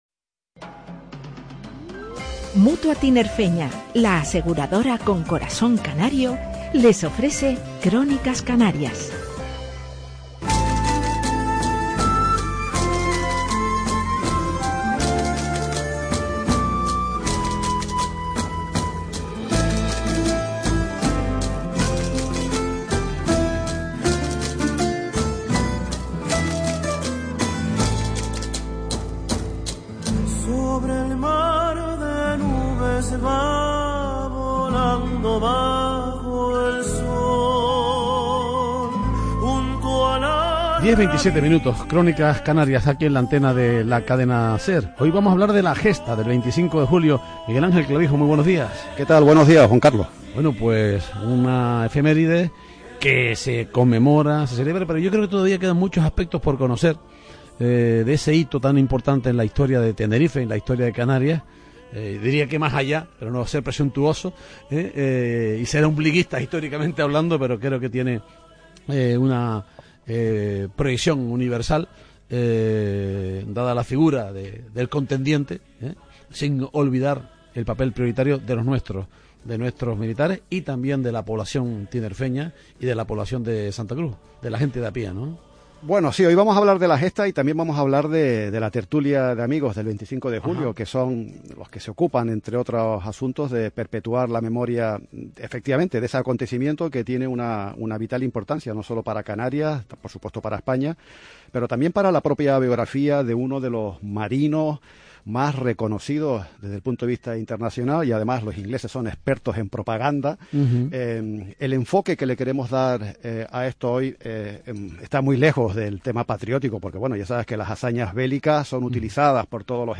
Entrevista radiofónica